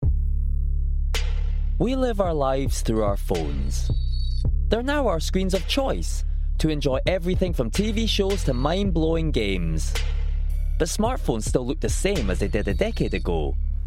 Commercial Archives - Page 2 of 2 - Jabbervoices
Edinburgh